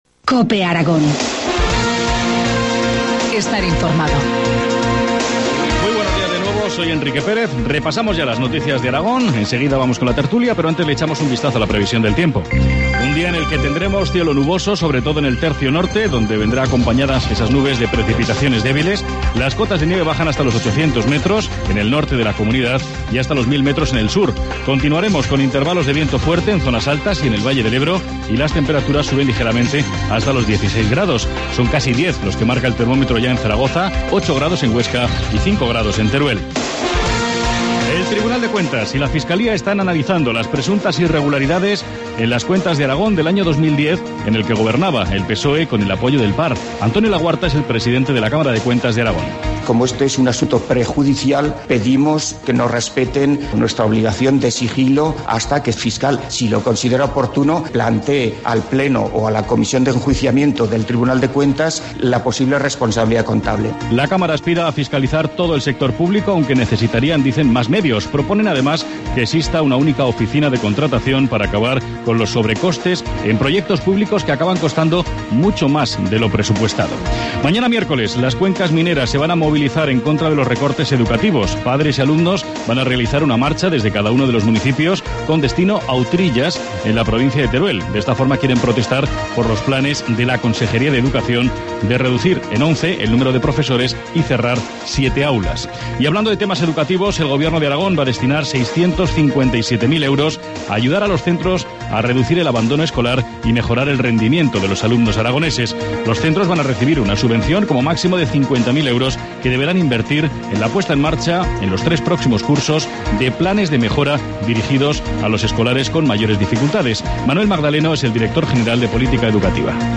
Informativo matinal, martes 5 de febrero, 8.25 horas